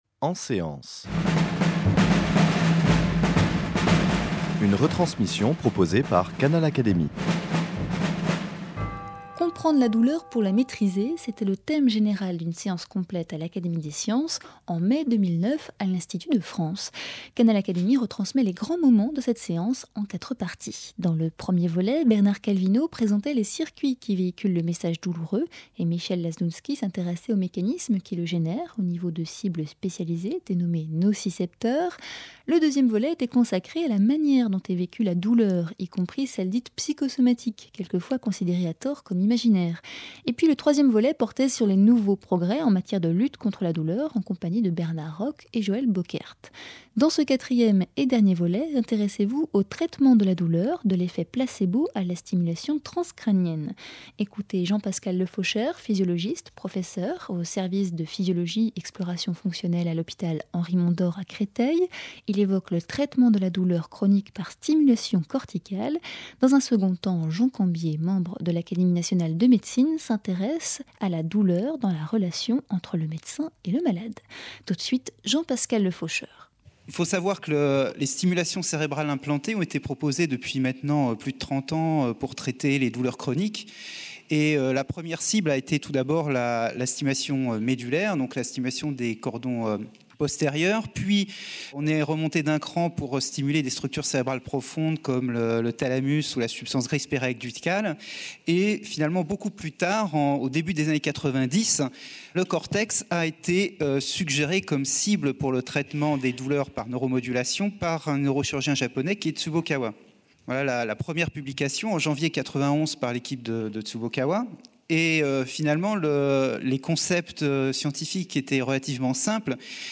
neurologiste se penchent sur ces deux méthodes, au cours d’une séance consacrée à la douleur à l’Académie des sciences en mai 2009.